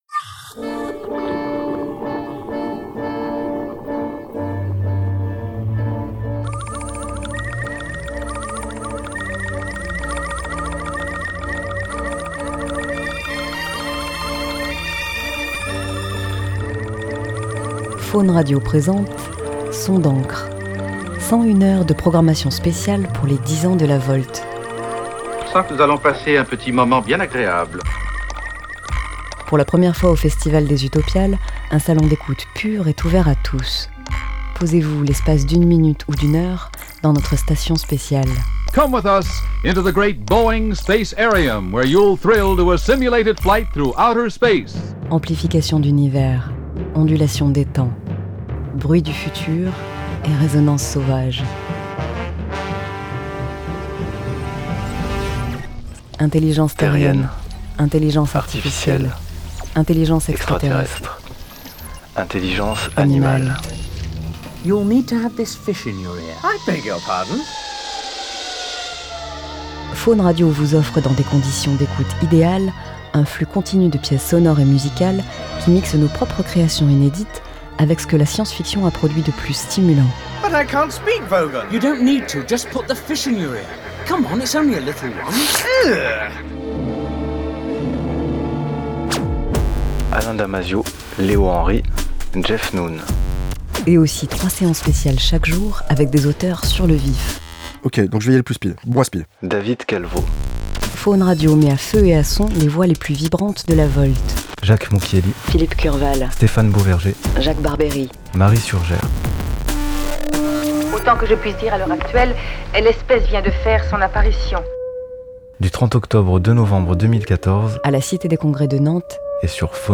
C'est une initiative de Phaune Radio, un salon d'écoute durant les Utopiales où vous pourrez, le temps de quelques minutes ou de plusieurs heures, vous laisser bercer par des sons venus d'ailleurs ou bien de chez nous, dans des pièces musicales mélangeant créations personnelles et productions science-fictionnesques.
Dans une ambiance feutrée, laissez-vous bercer par cette palette sonore inédite et inattendue.